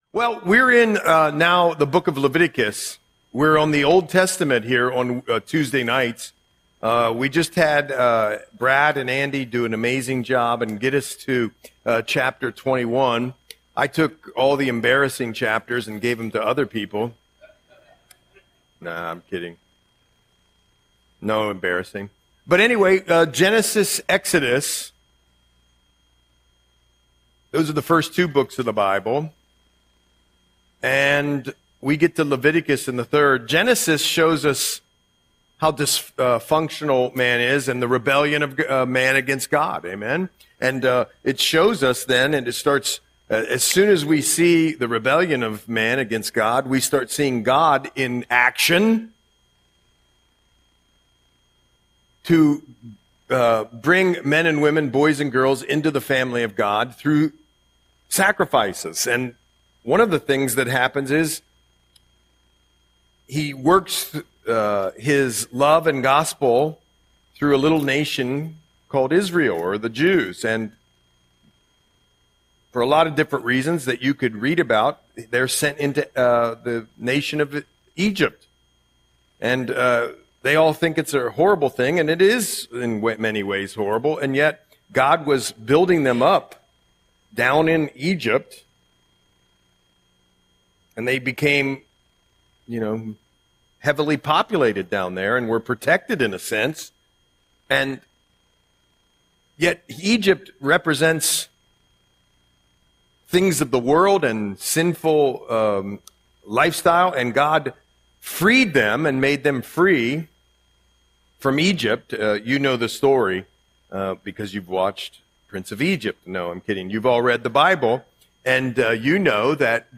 Audio Sermon - December 17, 2025